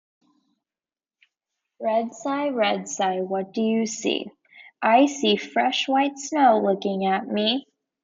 A repetitive, rhythmic chant follows Red Cy as it sees fresh white snow, elevator buttons, and Cy Ride, building to a final cumulative line.